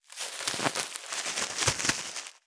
661ゴミ袋,スーパーの袋,袋,買い出しの音,ゴミ出しの音,袋を運ぶ音,
効果音